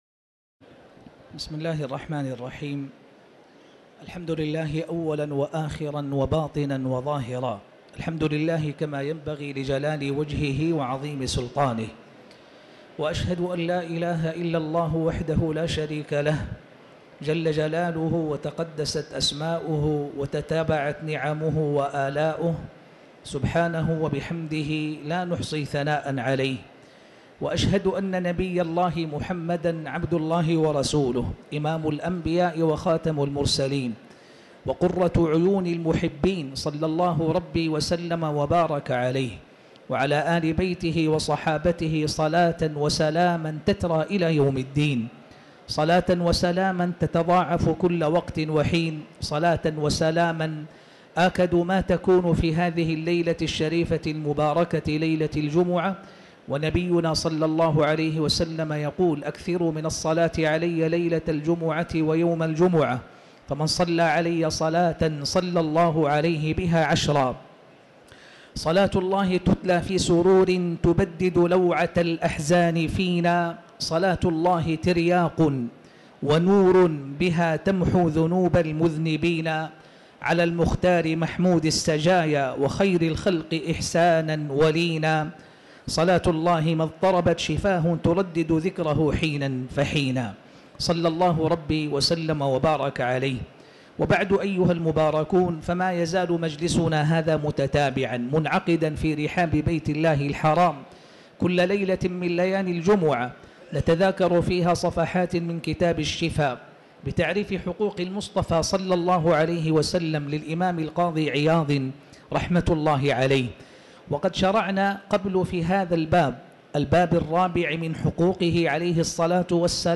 تاريخ النشر ١٤ رجب ١٤٤٠ هـ المكان: المسجد الحرام الشيخ